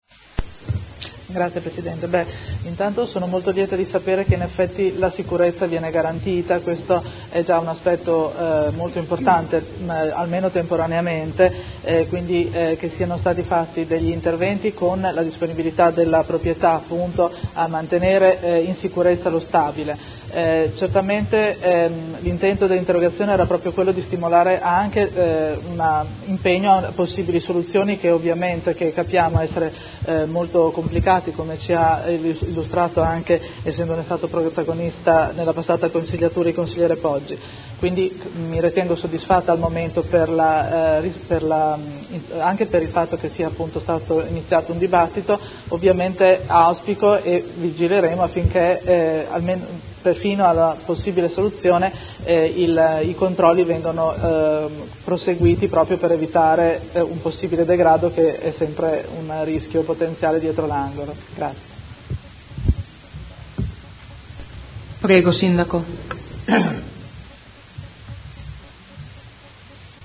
Seduta del 28/04/2016. Interrogazione della Consigliera Pacchioni (P.D.) avente per oggetto: Centro Direzionale Manfredini. Replica